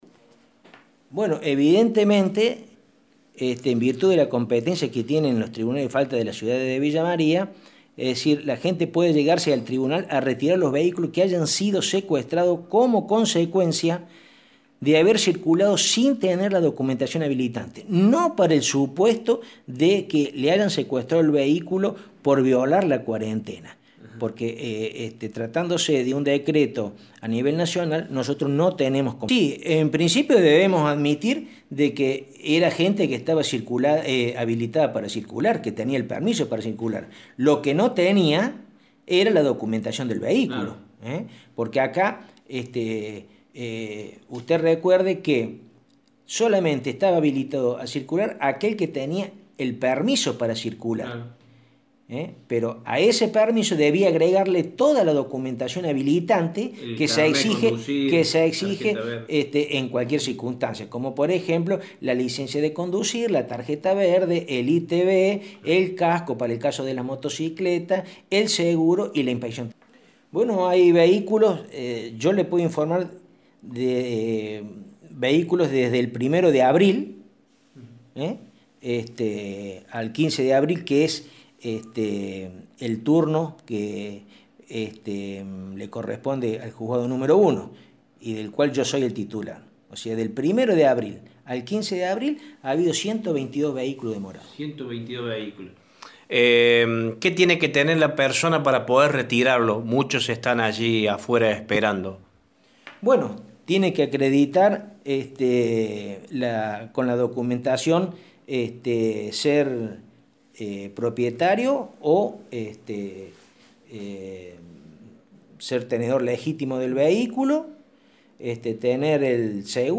El juez de Faltas municipal, el Dr. Héctor Paschetto indicó a Radio Show que, en 15 días, desde el 1 de abril al 15 del mismo mes, en su juzgado hay 122 vehículos secuestrados por falta de documentación para circular o faltas de tránsito.